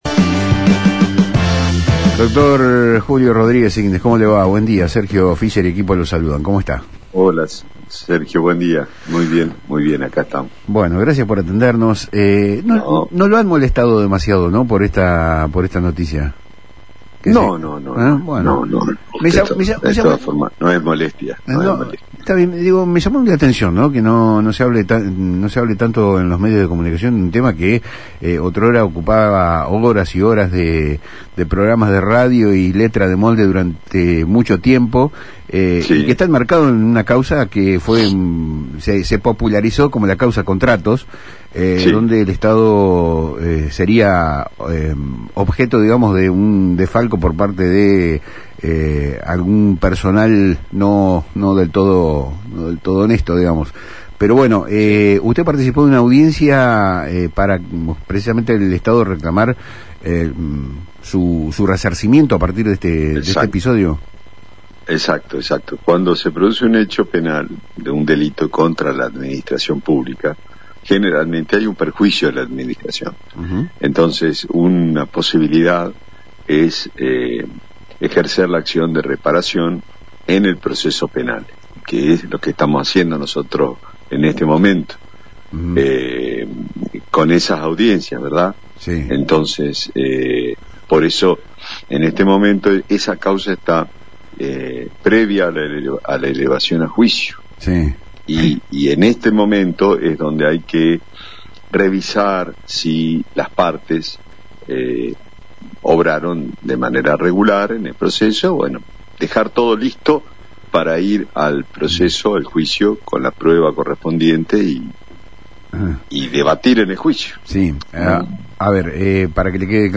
El Dr. Julio Rodríguez Signes, Fiscal de Estado de la provincia de Entre Ríos, habló con Palabras Cruzadas en FM Litoral sobre los avances de la “Causa Contratos”, una investigación que busca el resarcimiento económico del Estado tras un presunto desfalco.
Durante la entrevista radial, el funcionario también se refirió a las demandas de la provincia contra la Nación, incluyendo los reclamos vinculados con el complejo Salto Grande y ANSES.